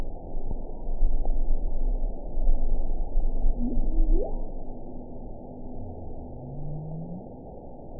event 918988 date 12/22/23 time 12:25:02 GMT (1 year, 4 months ago) score 9.24 location TSS-AB07 detected by nrw target species NRW annotations +NRW Spectrogram: Frequency (kHz) vs. Time (s) audio not available .wav